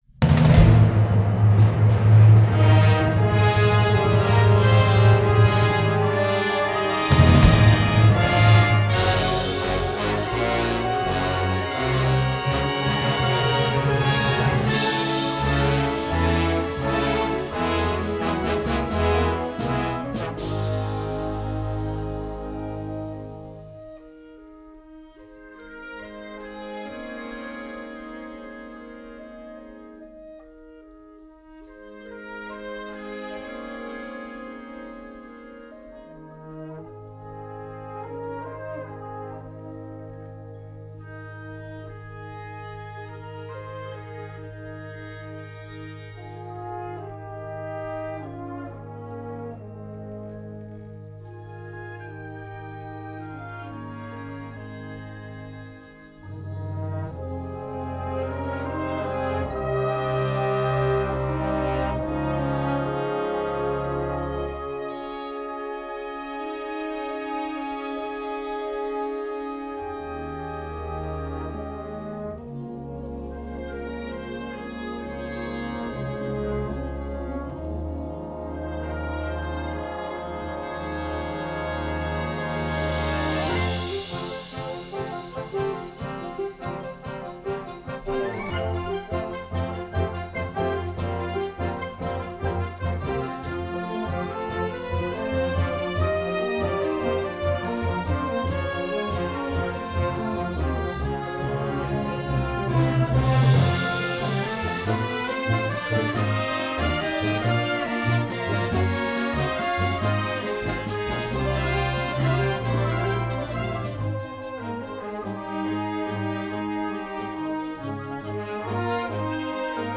Gattung: Konzertwerk
Besetzung: Blasorchester
Nun setzt in mehreren Instrumentengruppen eine Fuge ein.